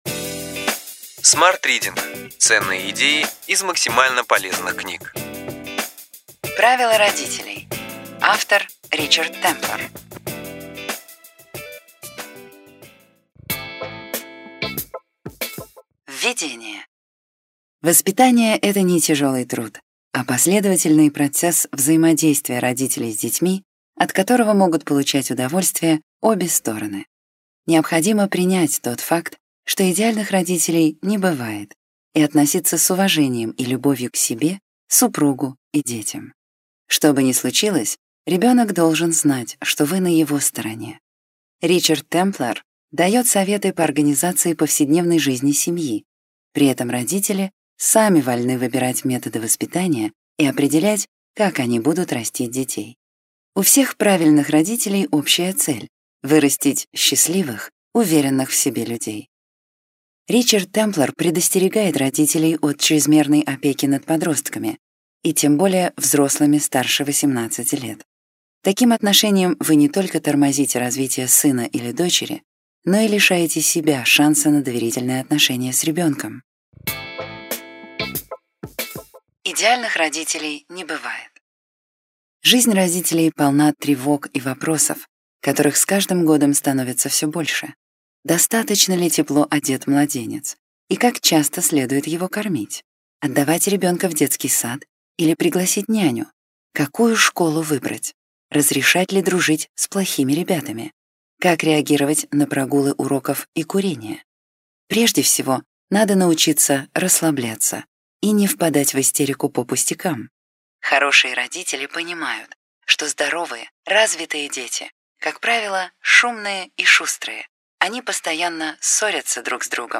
Аудиокнига Ключевые идеи книги: Правила родителей. Ричард Темплар | Библиотека аудиокниг